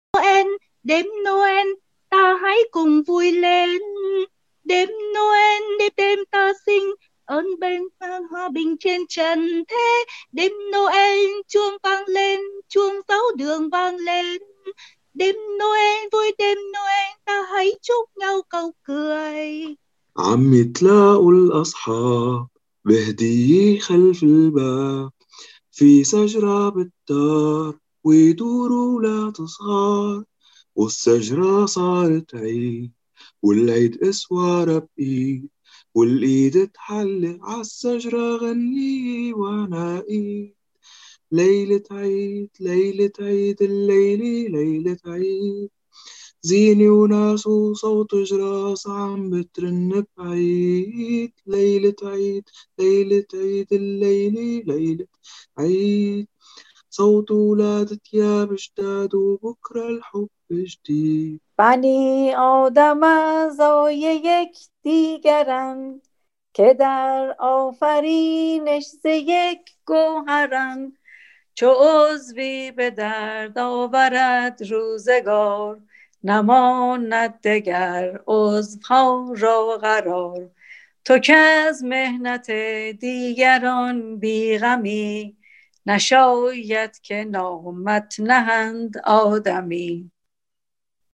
Im Dezember 2020 trafen sich Teilnehmer*innen aus dem Projekt online, um zusammen zu singen.
Durch das gemeinsame Singen wurde ein Gefühl der Zusammengehörigkeit geschaffen, obwohl jede*r Teilnehmer*in allein zu hause vor dem Computer saß. Zusammen sangen die Teilnehmer*innen Weihnachtslieder in verschiedenen Sprachen und Lieblingslieder aus der Heimat. Ein paar Sänger*innen sind hier zu hören:
Lieder-zusammen.mp3